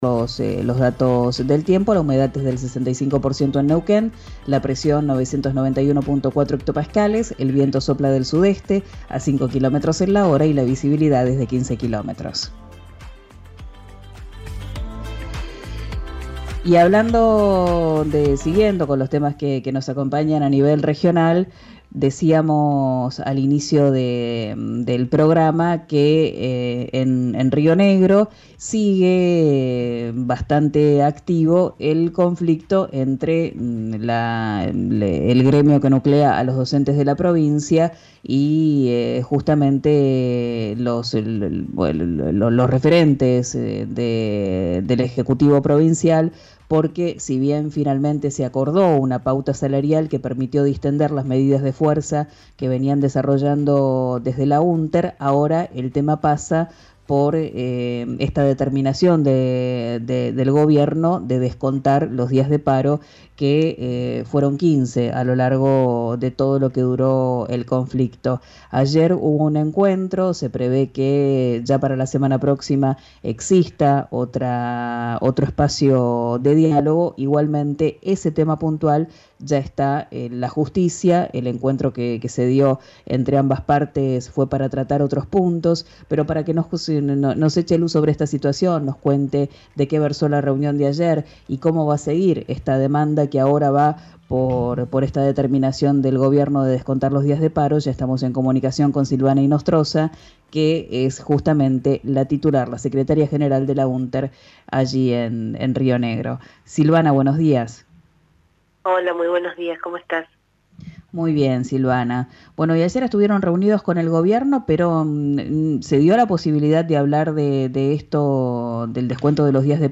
En diálogo con RÍO NEGRO RADIO repudió el modo en que el paro figura en sus recibos de sueldo: como inasistencia injustificada.